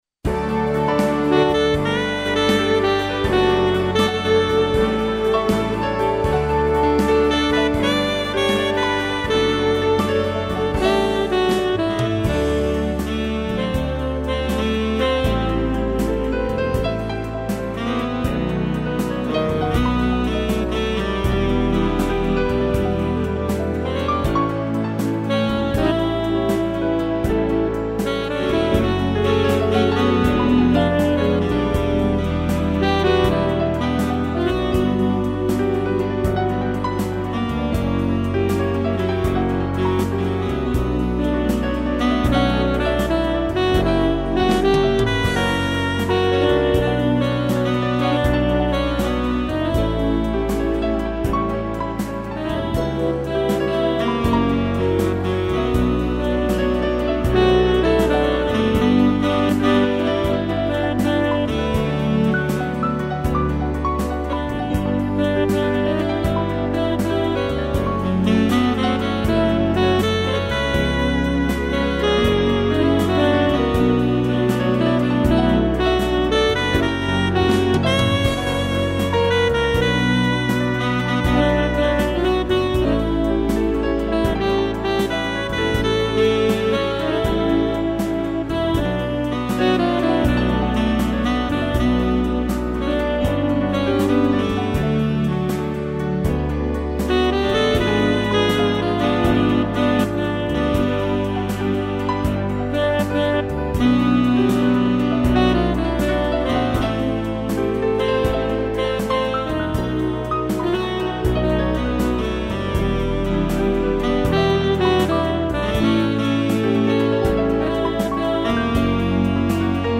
piano, sax e órgão
(instrumental)